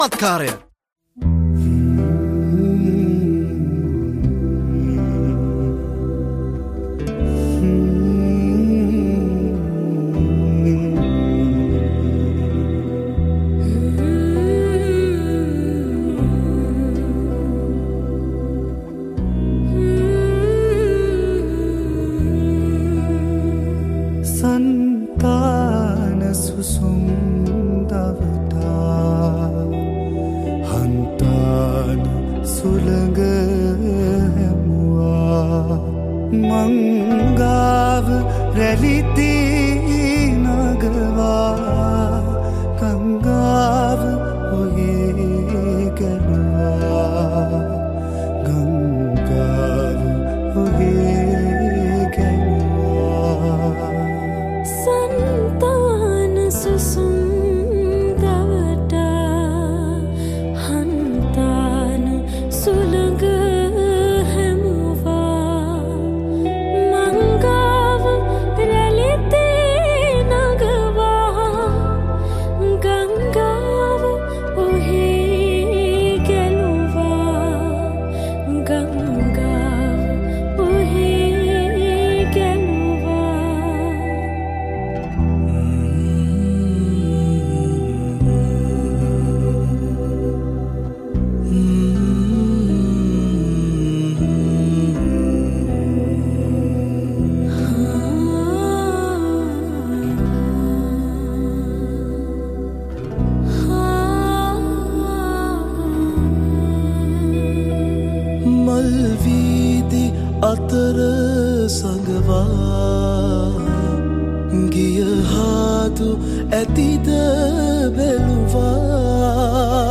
Group Song